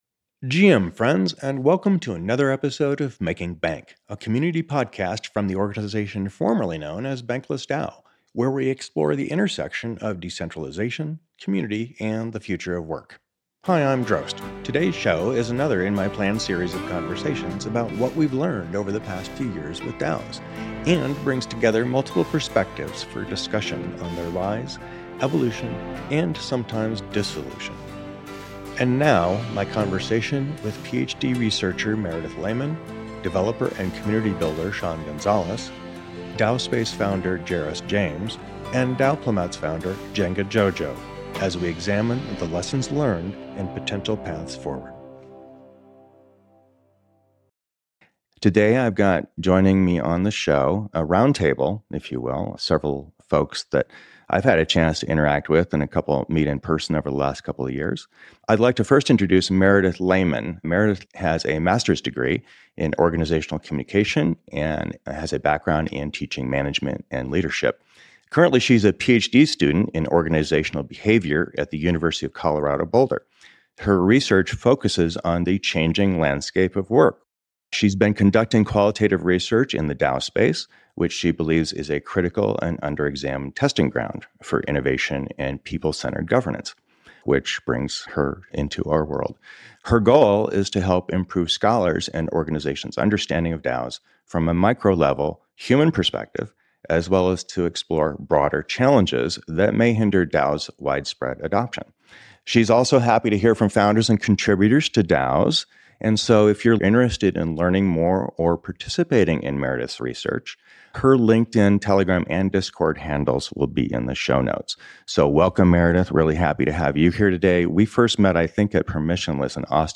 1 DAO Experience Roundtable | A candid conversation on working and building in Web3 1:01:31